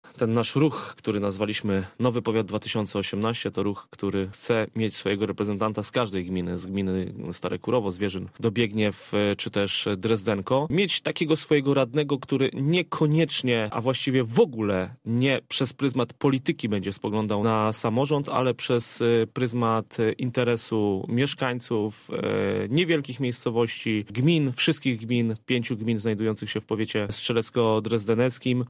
Ruch „Nowy powiat 2018” chce zawalczyć o swoich reprezentantów w radzie powiatu strzelecko-drezdeneckiego. Mateusz Karkosza zastępca burmistrza Strzelec Krajeńskich mówi, że czas na zmiany pokoleniowe w tych strukturach.
Gość Radia Zachód podkreślał, że ruch chce wprowadzić do rady powiatu przedstawicieli, którzy będą dbać o interesy mieszkańców.